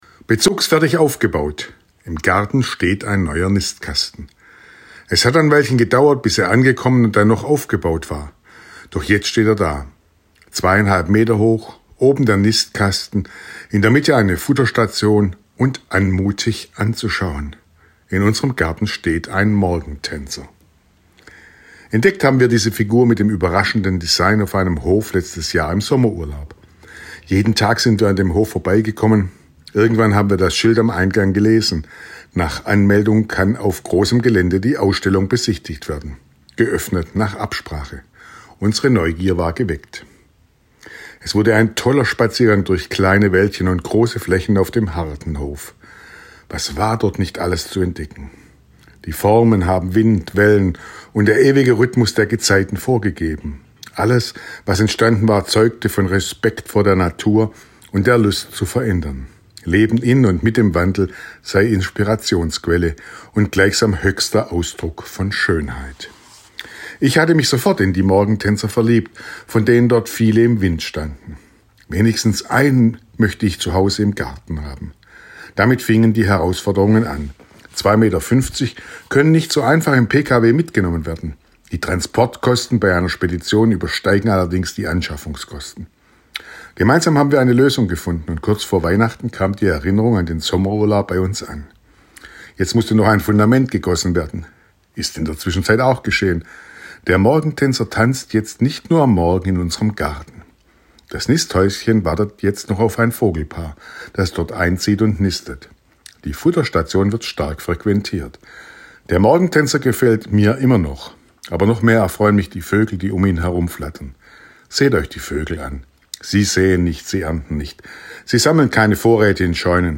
Radioandacht vom 2. Mai